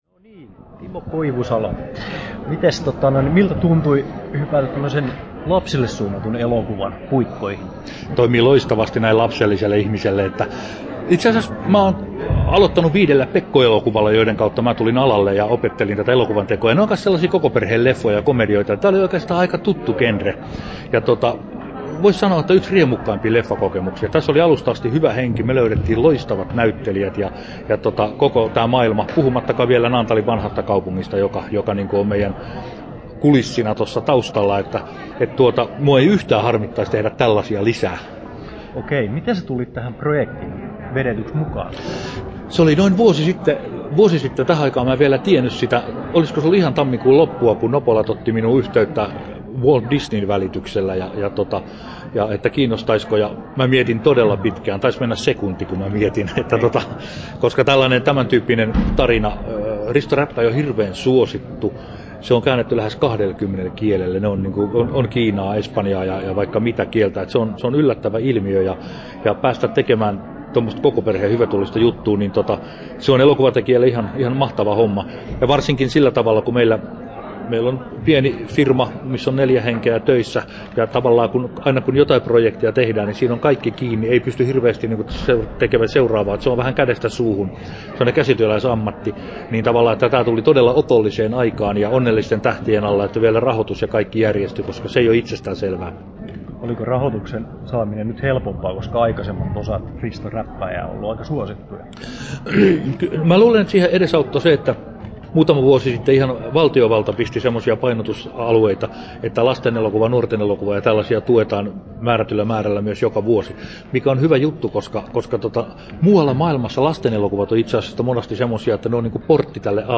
Haastattelussa Timo Koivusalo Kesto: 11'50" Tallennettu: 22.1.2014, Naantali Toimittaja